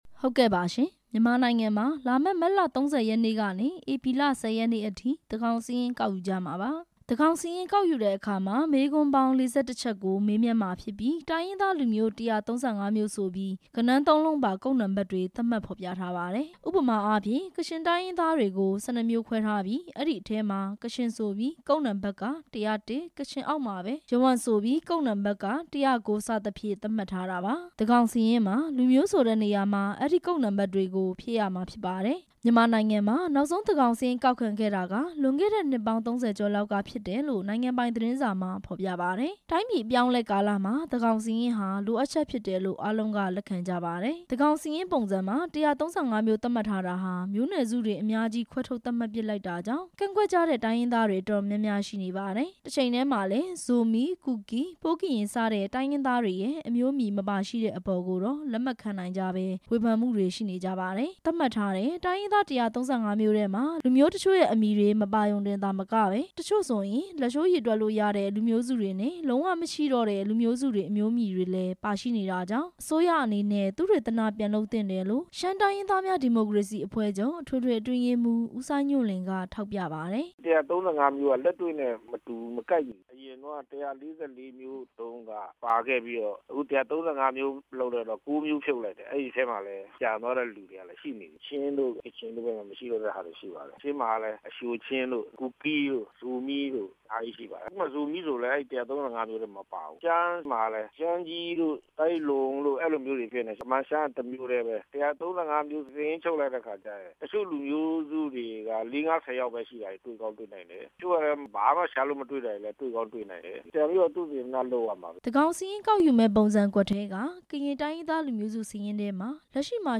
တိုင်းရင်းသားလူမျိုး ခေါင်းဆောင်တစ်ချို့ရဲ့ ဖြေကြားချက်များ